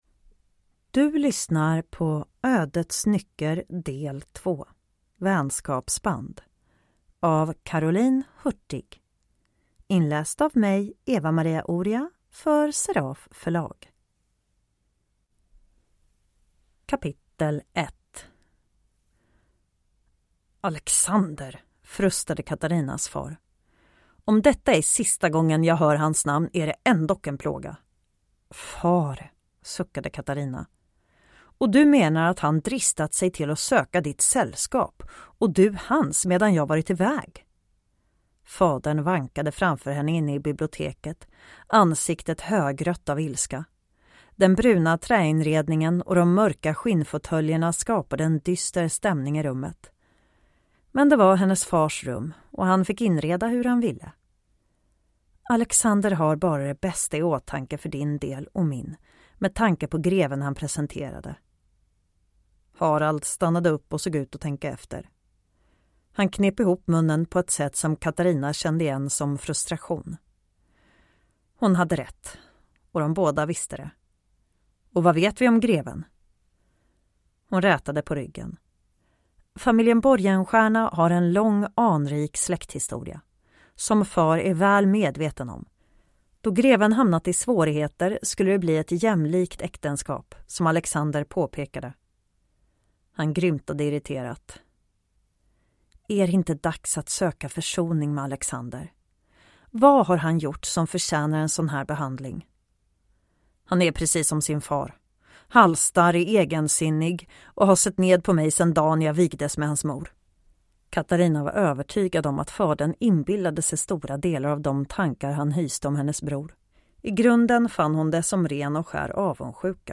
Ödets nycker: Vänskapsband / Ljudbok